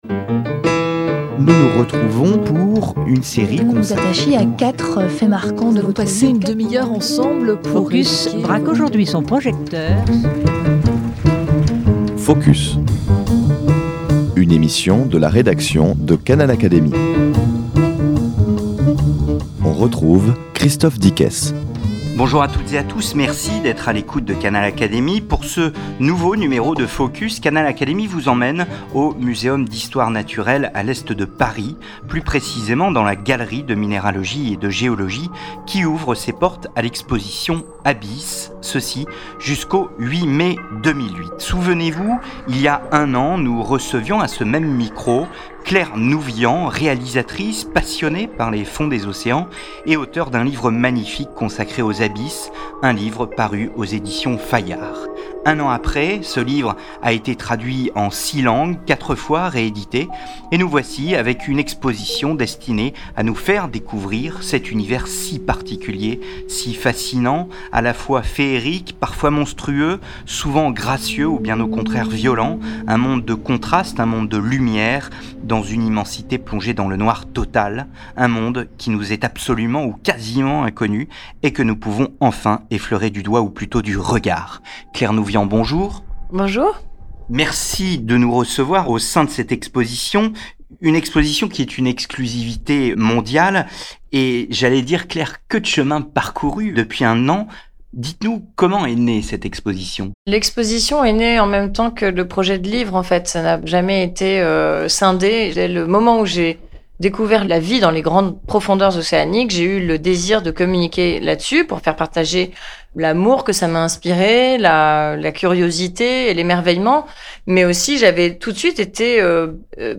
Dans cette émission Focus, Canal Académie vous emmène au Muséum national d’Histoire naturelle de Paris, dans la Galerie de Minéralogie et de Géologie qui ouvre ses portes à l’exposition Abysses, ceci jusqu’au 8 mai 2008.